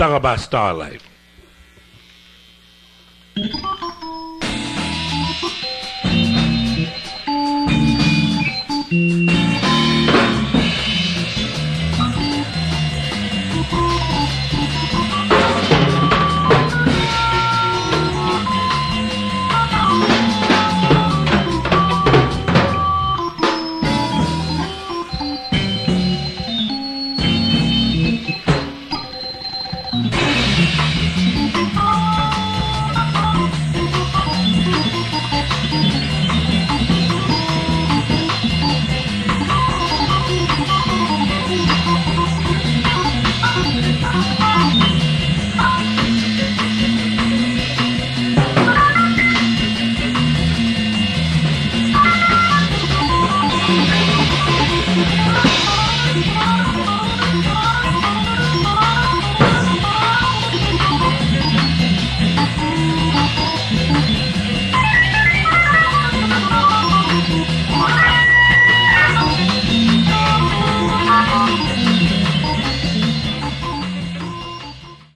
la registration est particulière ainsi que le mix general
c'est surement un live ou un vieil enregistrement
c'est un enregistrement d'amateur, tout simple